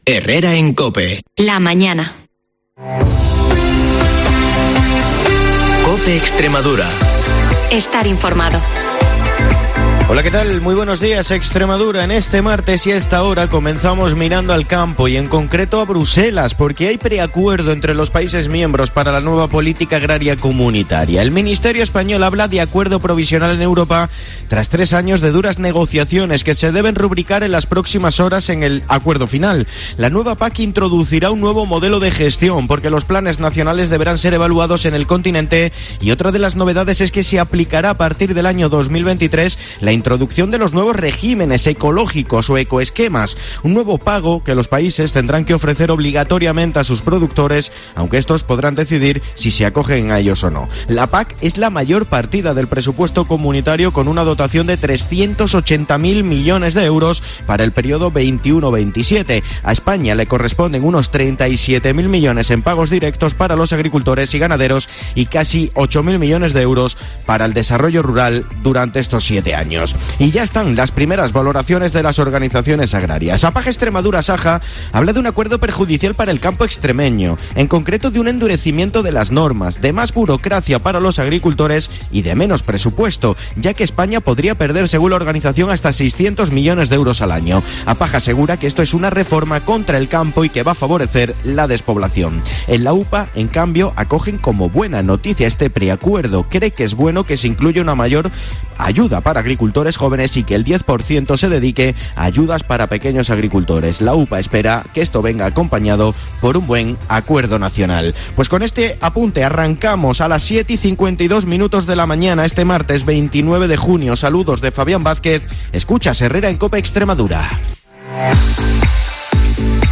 el informativo más escuchado de Extremadura.